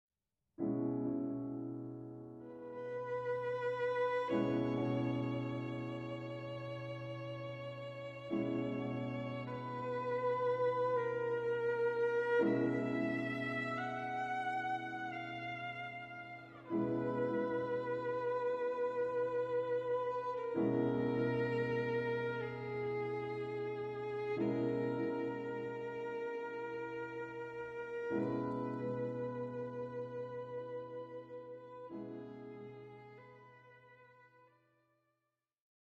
Clarinet
Cello
Piano
Violin